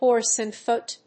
hórse and fóot＝hórse, fóot and dragóons